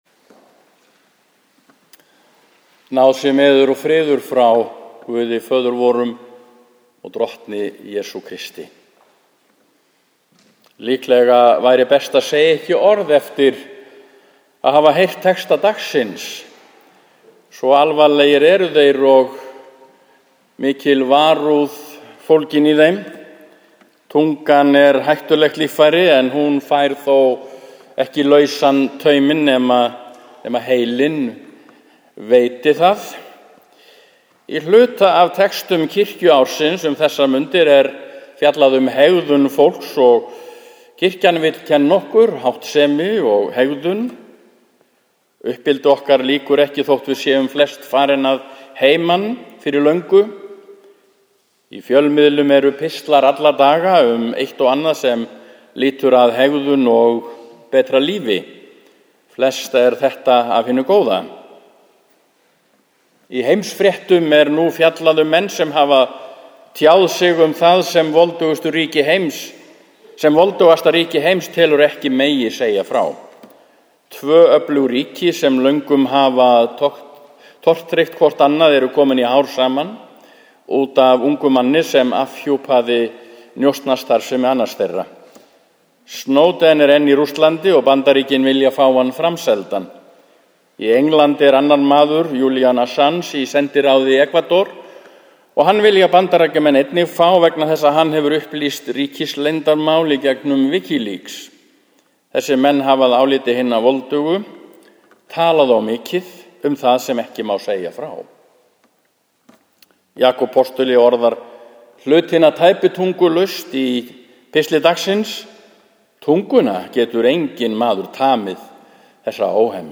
Prédikun í Neskirkju sunnudaginn 18. ágúst 2013 - trin 13 2013 B Einhver innskot eru í ræðunni sem ekki eru í textanum.